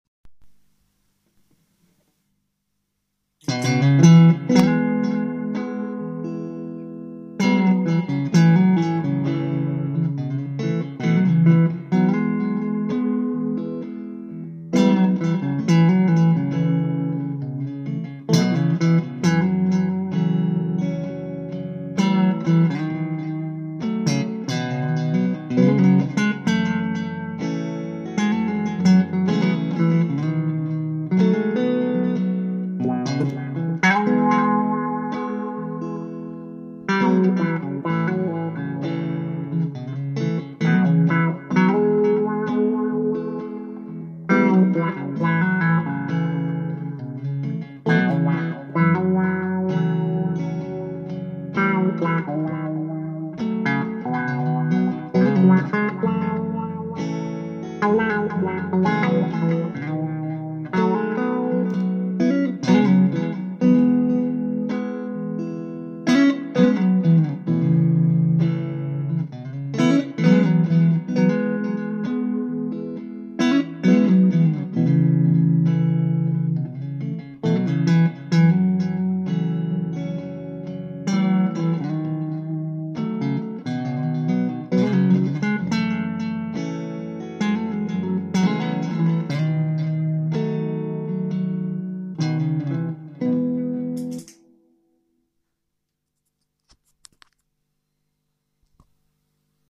So klingt der Amp: habe nur mal das Handy-mikro gerade davor gehalten, mit Looper,Delay und Wah-pedal etwas improvisiert. (Im laufenden Betrieb ist gar kein Brummen zu hören) Man hört sogar wie ich den Looper ausschalte.